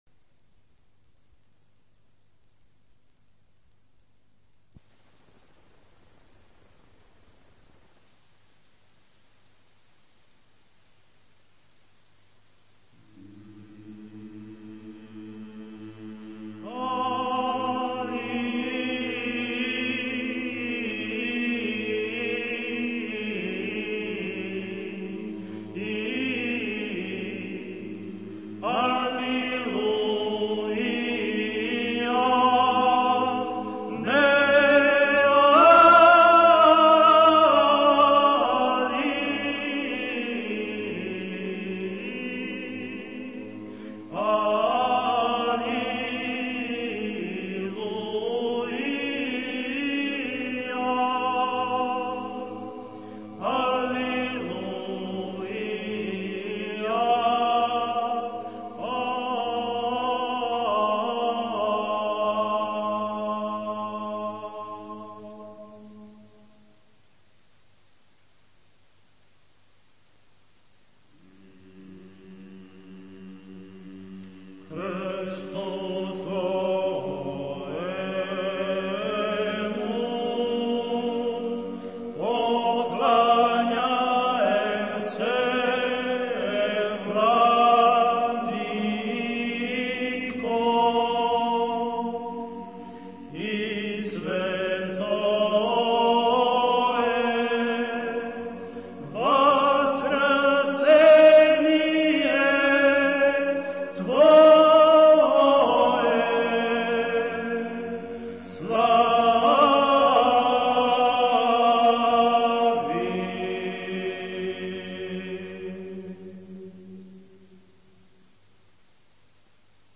Hudba » Duchovní hudba » Srbské pravoslavné zpěvy 13.-18. století
1422-srbske-pravoslavne-zpevy.mp3